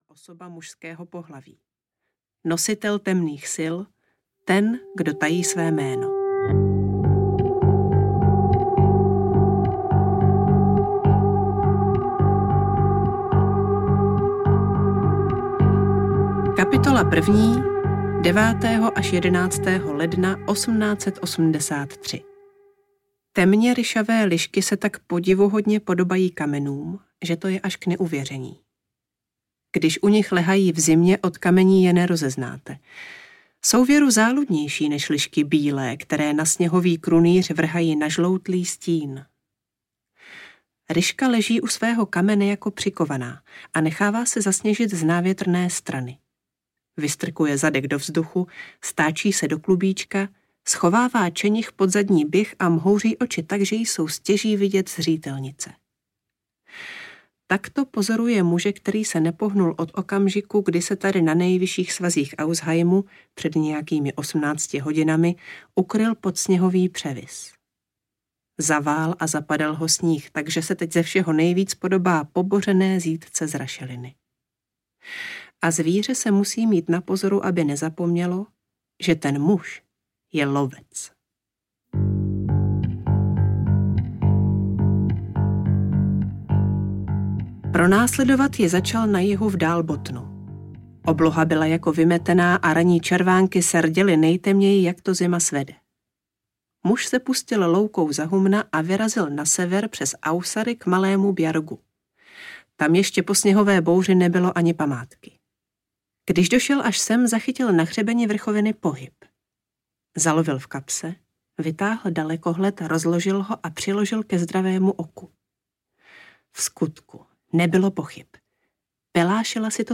Syn stínu audiokniha
Ukázka z knihy